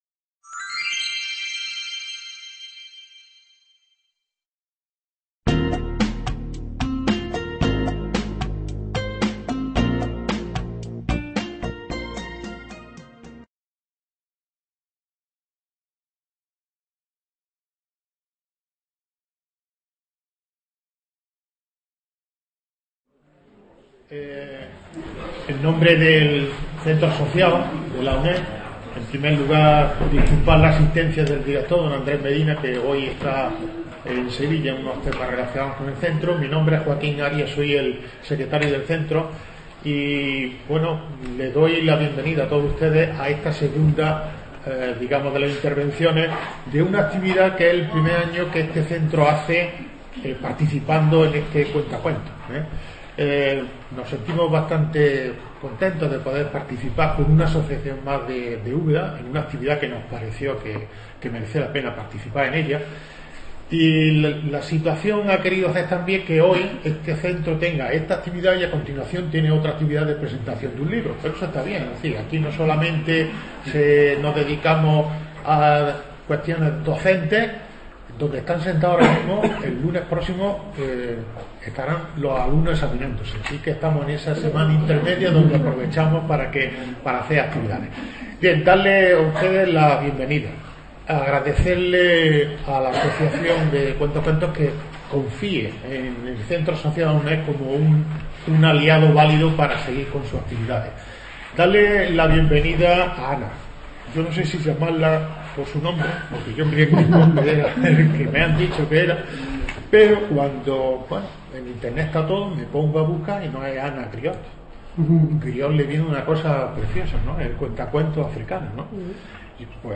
conferencia